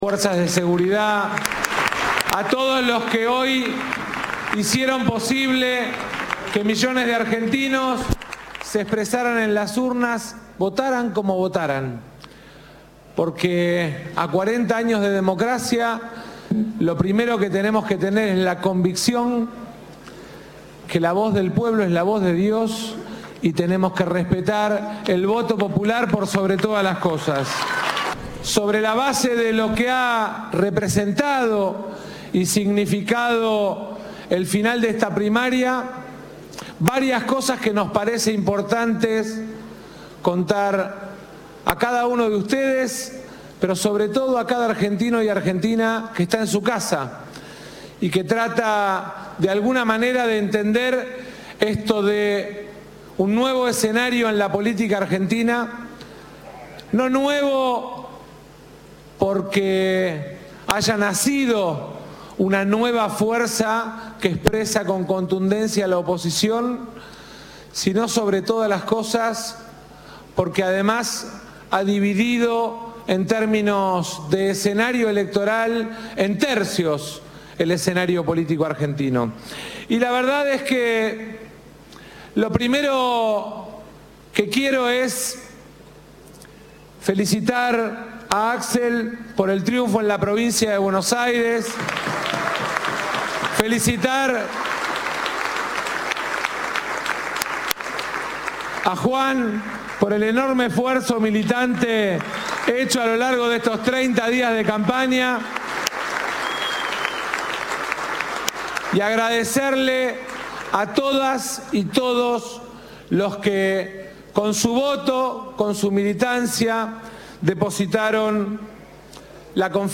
"Vamos a dejar hasta nuestra última gota de transpiración para ganar en octubre, ganar en noviembre y seguir siendo gobierno en la Argentina", resaltó Massa desde el búnker oficialista montado en el barrio porteño de Chacarita.